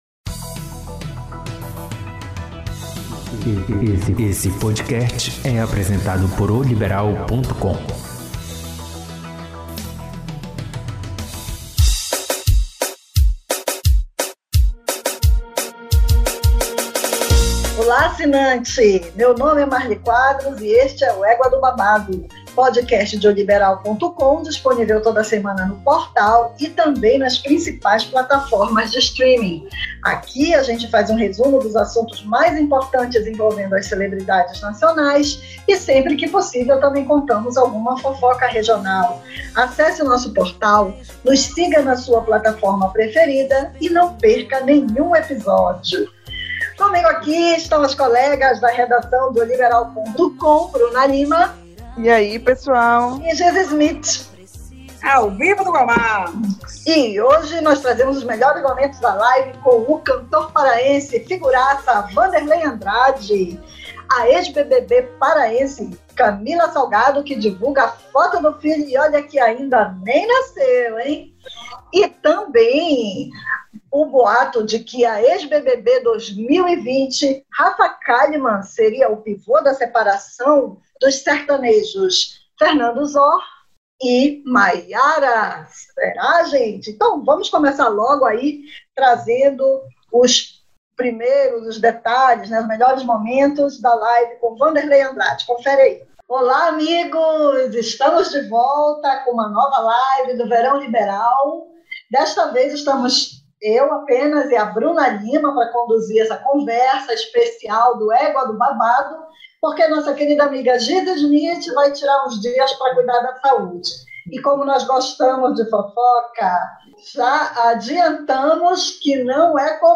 PODCAST ÉGUA DO BABADO! Wanderley Andrade conta suas histórias na live do Verão Liberal